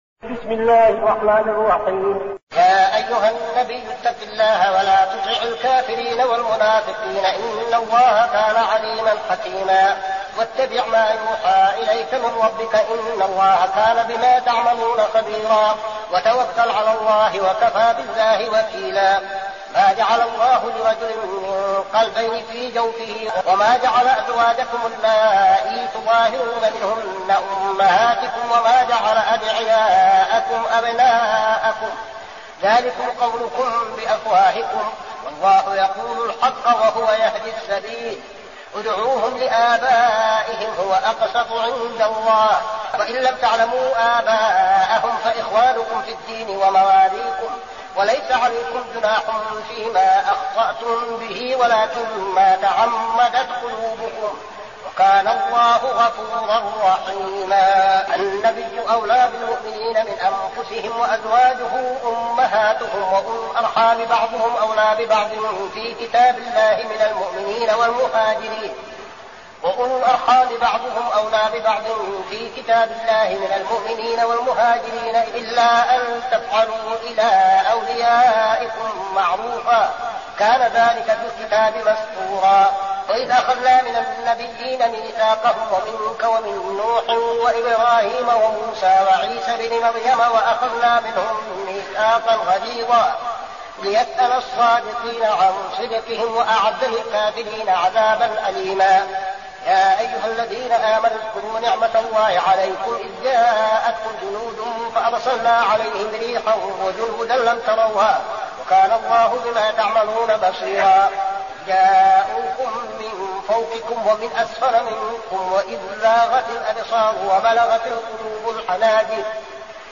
المكان: المسجد النبوي الشيخ: فضيلة الشيخ عبدالعزيز بن صالح فضيلة الشيخ عبدالعزيز بن صالح الأحزاب